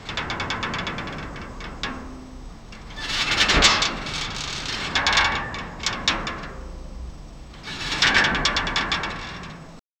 subtle-metal-locker-expan-soqsxwnq.wav